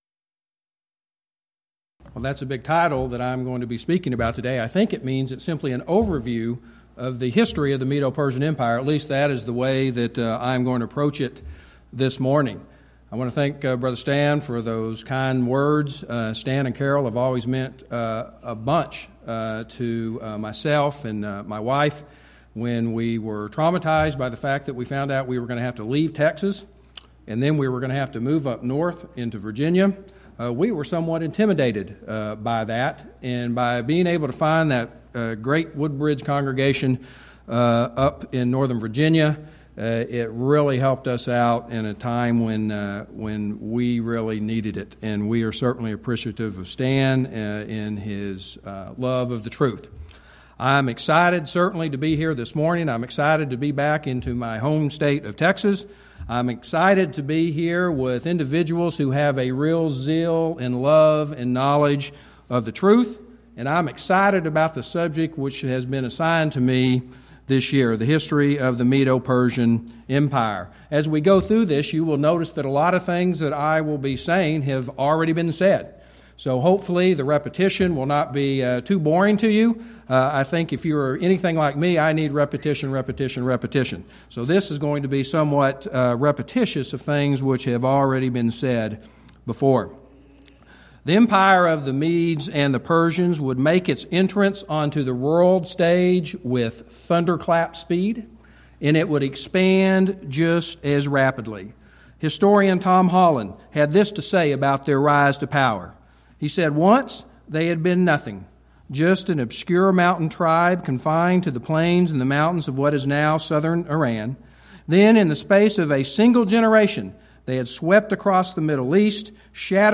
Event: 11th Annual Schertz Lectures
lecture